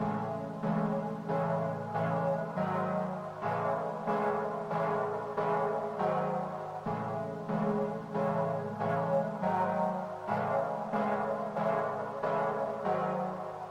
陷阱钢琴3
描述：ap钢琴140bpm
Tag: 140 bpm Trap Loops Piano Loops 2.31 MB wav Key : Unknown